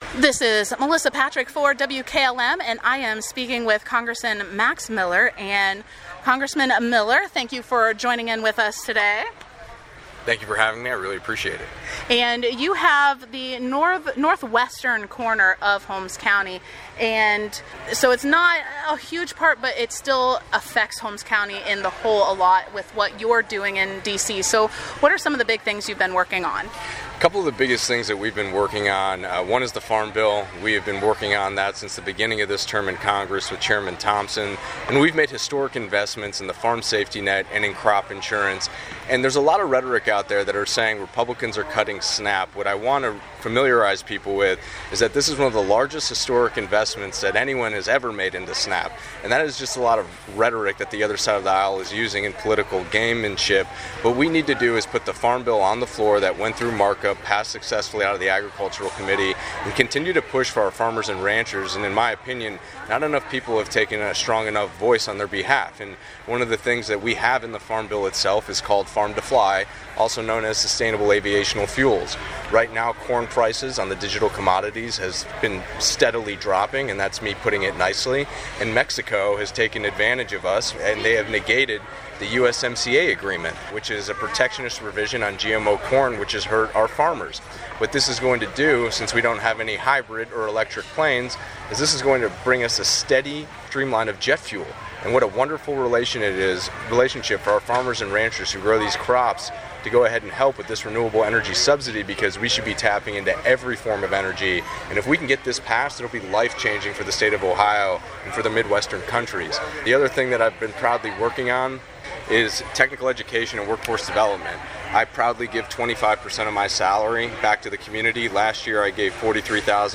8-12-24 Interview with Congressman Max Miller